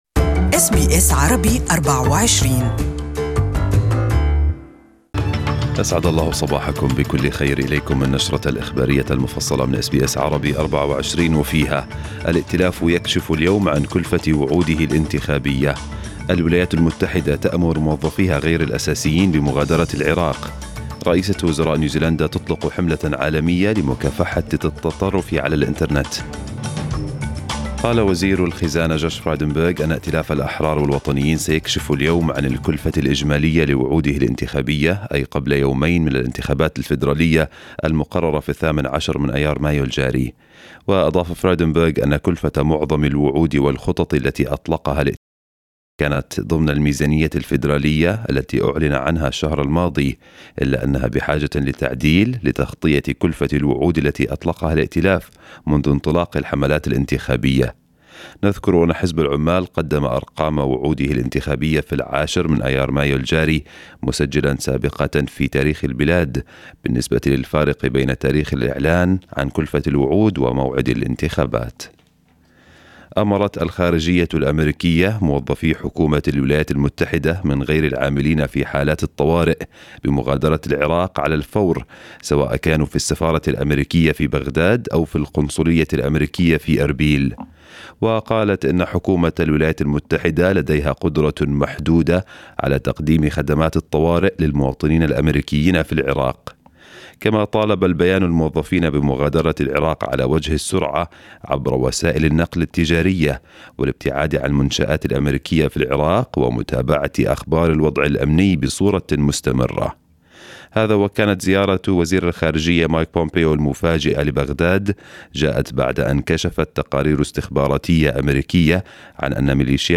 News Bulletin in Arabic for the day